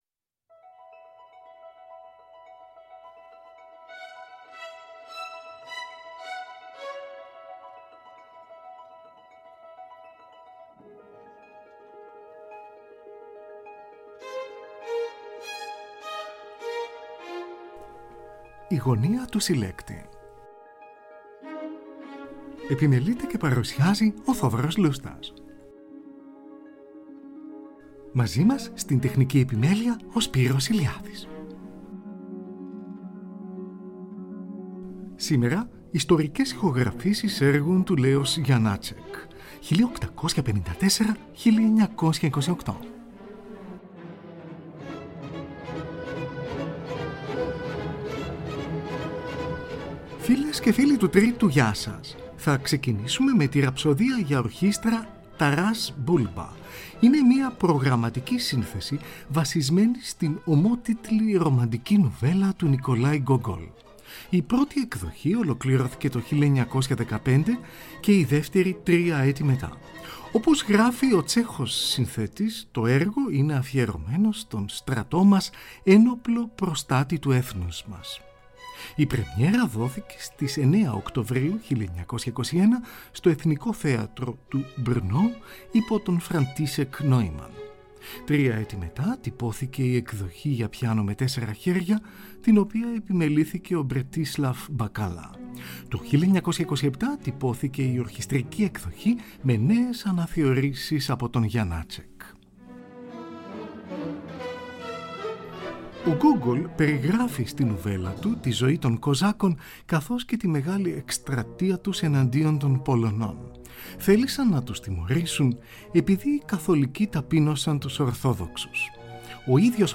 Σονάτα για βιολί και πιάνο. Τον βιολιστή Bronisław Gimpel συνοδεύει ο πιανίστας Martin Krause . Ηχογράφηση της Ραδιοφωνίας RIAS του Βερολίνου, στις 29 Ιουνίου 1955 .